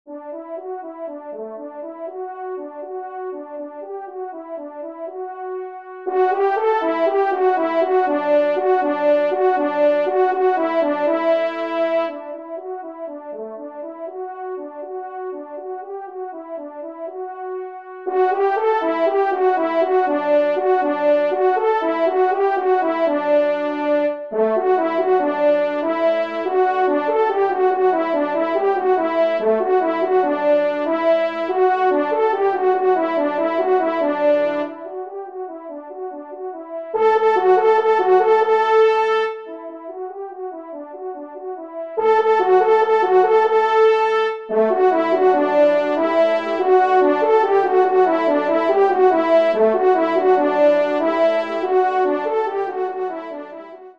Genre :  Divertissement pour Trompes ou Cors en Ré
2e Trompe